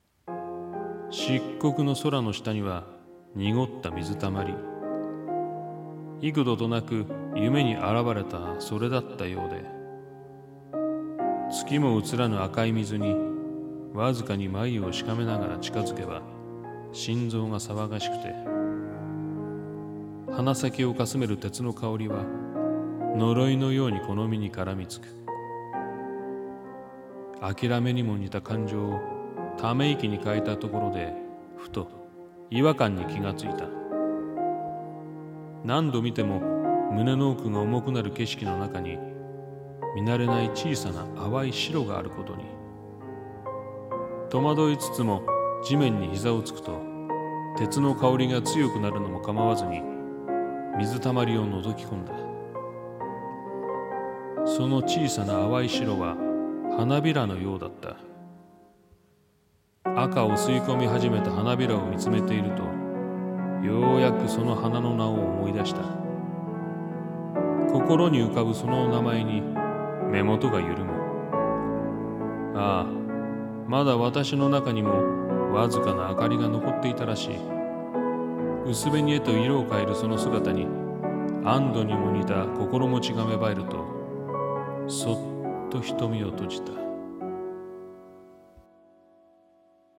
一人声劇】漆黒を照らすは花明かり